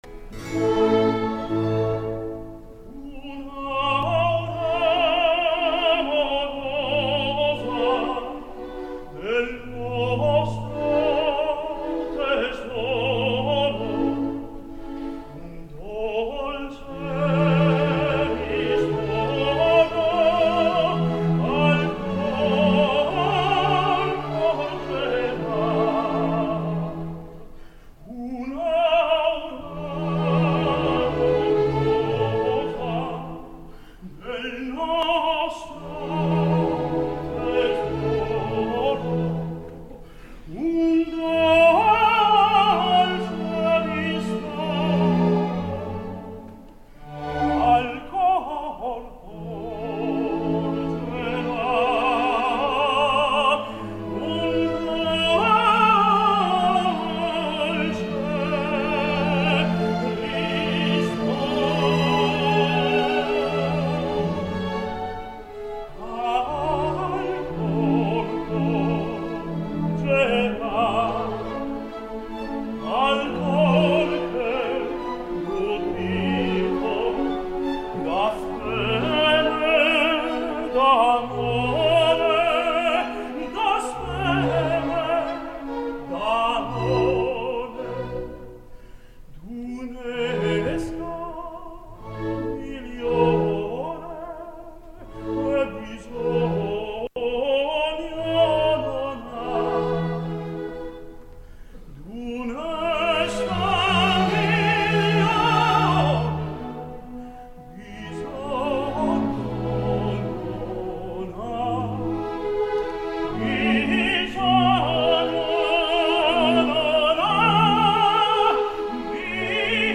LA Opera, 18 de setembre de 2011.